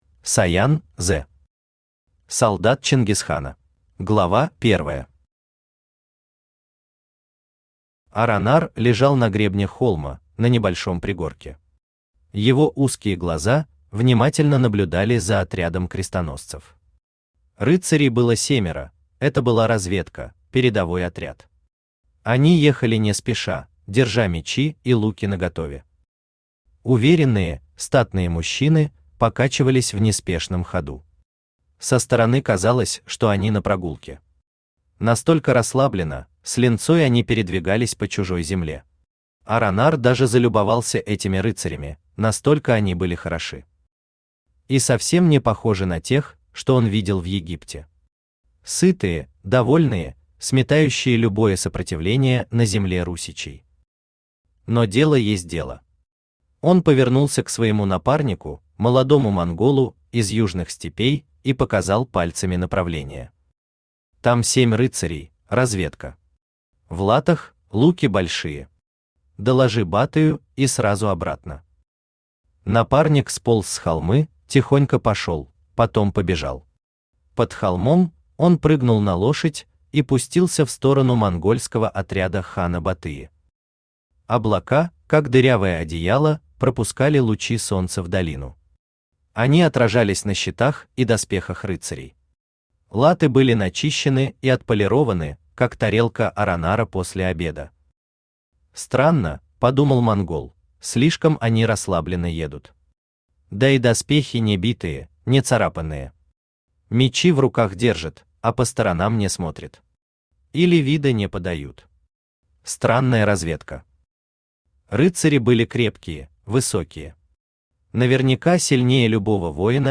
Aудиокнига Солдат Чингисхана Автор Саян З. Читает аудиокнигу Авточтец ЛитРес.